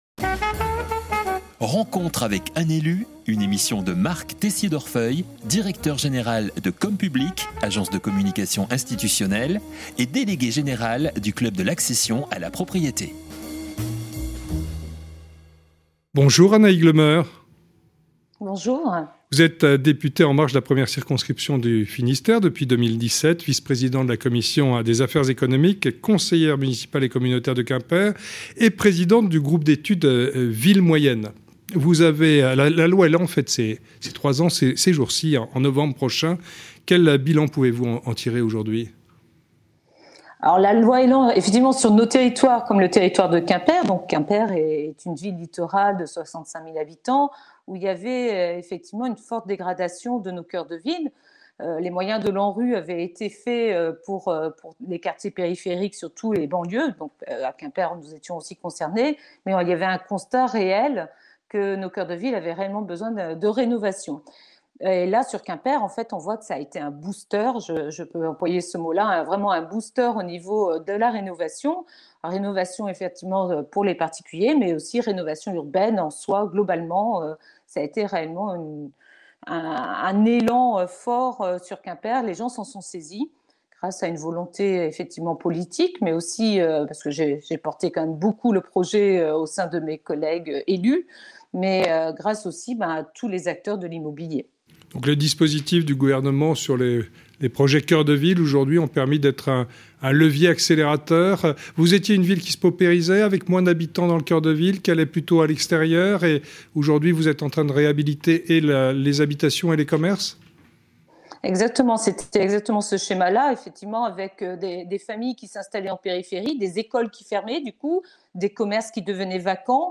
Conférence de presse NEXITY - Interview